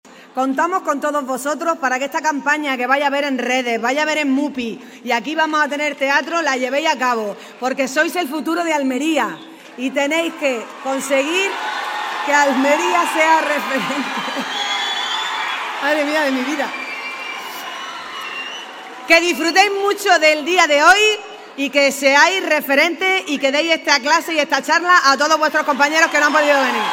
ALCALDESA-DESARROLLO-DE-LA-CAMPANA-Y-ANIMA-A-LOS-JOVENES-A-SER-PARTE-DE-LA-MISMA.mp3